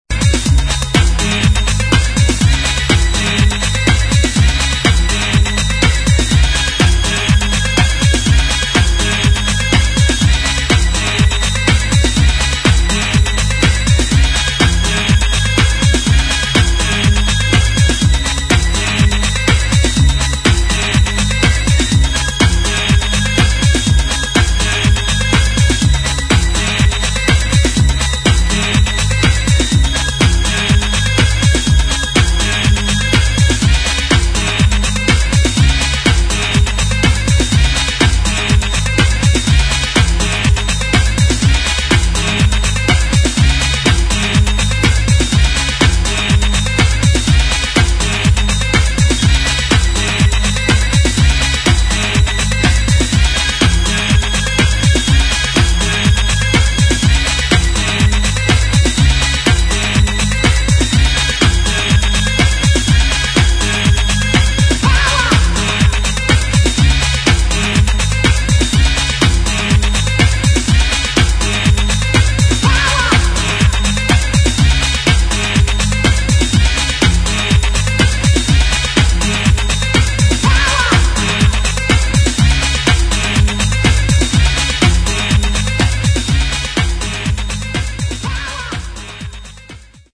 [ DEEP HOUSE | TECHNO ]